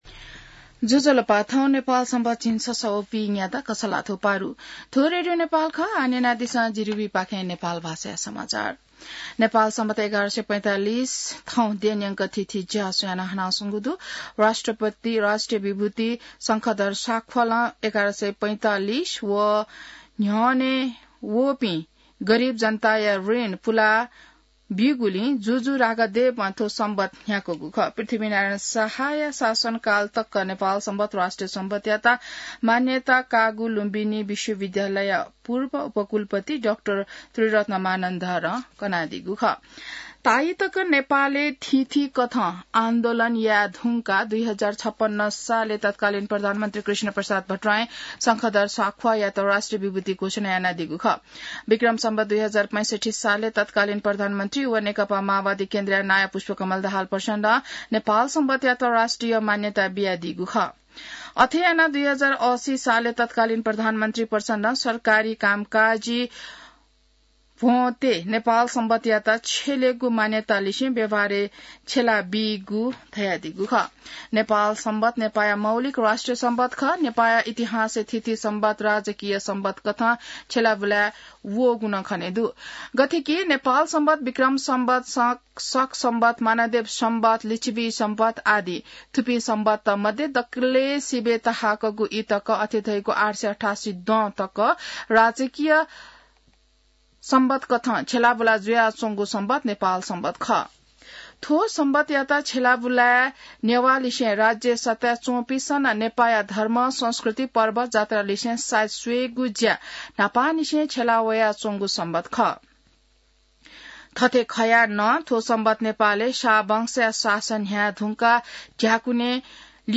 नेपाल भाषामा समाचार : १८ कार्तिक , २०८१